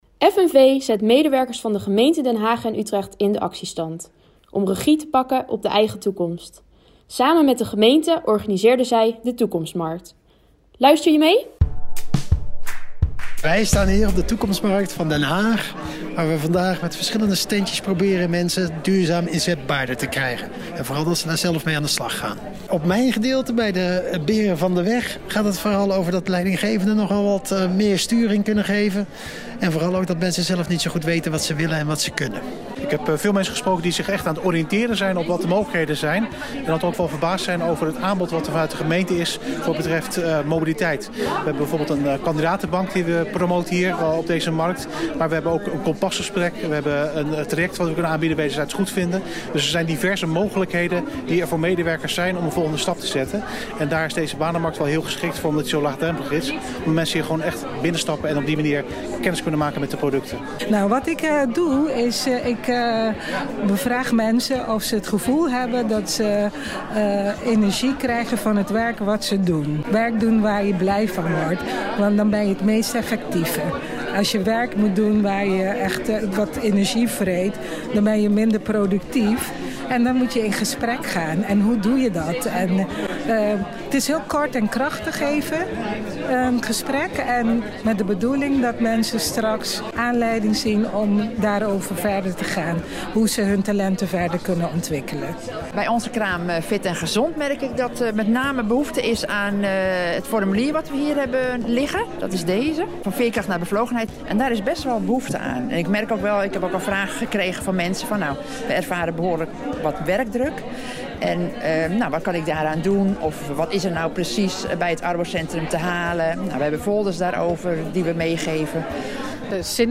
het geluidsfragment hoor je wat de markt bij deelnemers teweeg heeft gebracht.